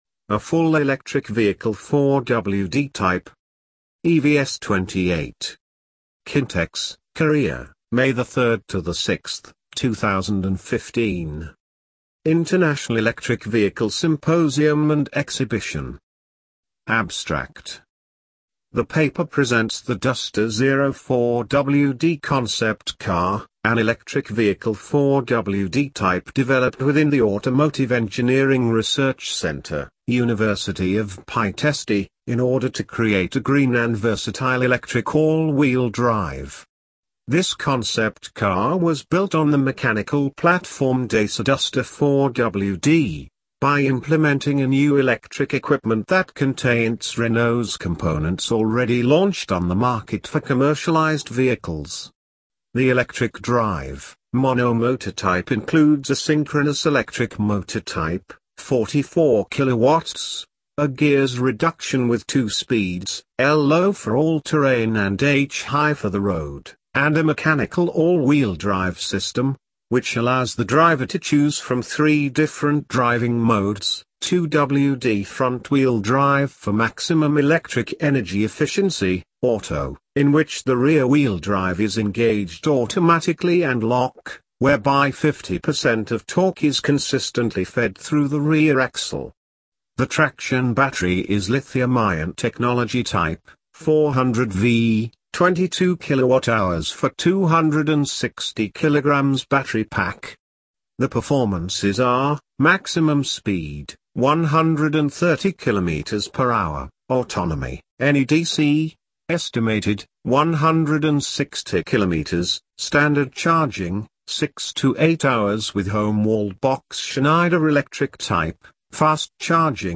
Full Electric Vehicle 4WD Type - Robot.mp3